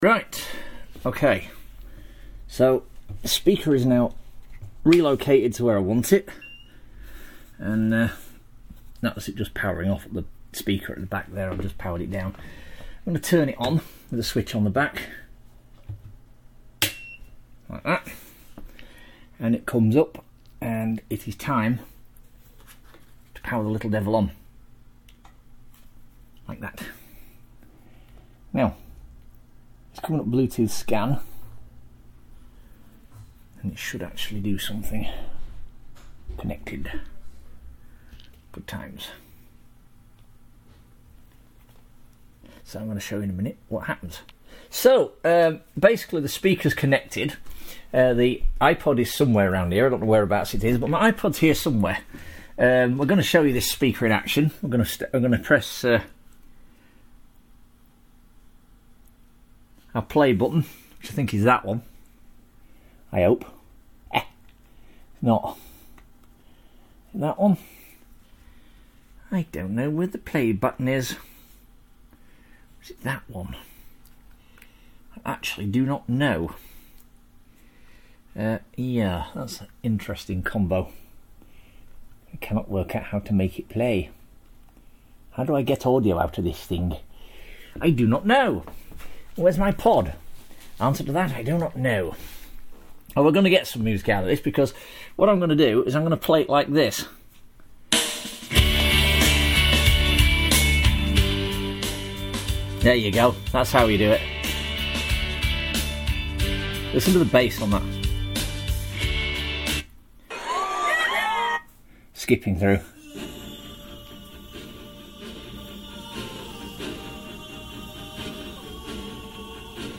exibel BDX 1400 speaker part 3, lets hear it in action! this thing is nice!